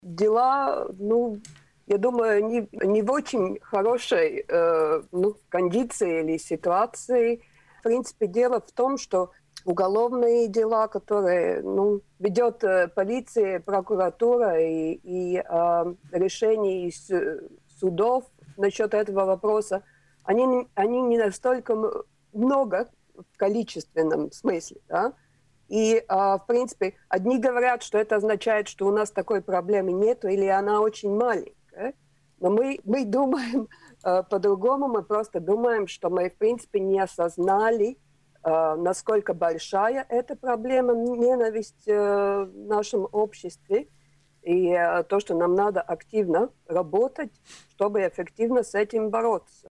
Сегодня на радио Baltkom обсуждались такие темы как нетерпимость и ксенофобия в Латвии, будет ли Латвия закупать российскую вакцину, как себя чувствует авиаотрасль в период пандемии.